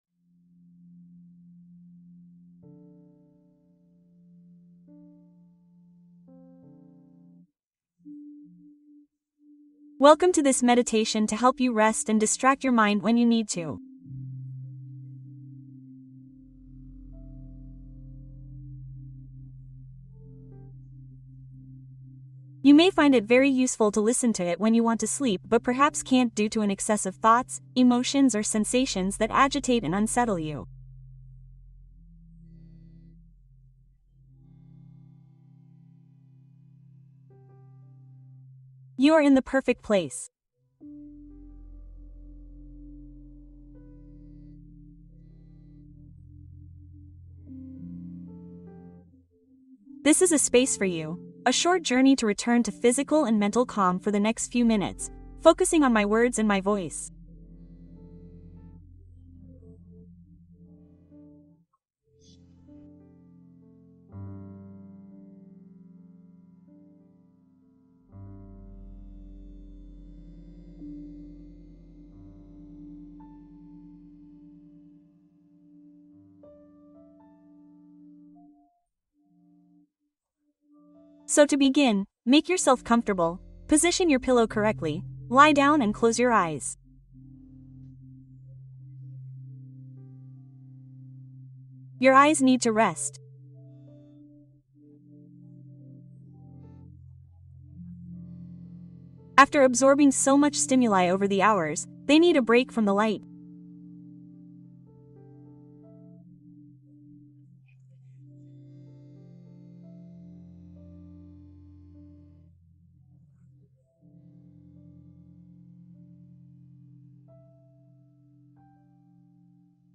Relato sereno para favorecer un sueño reparador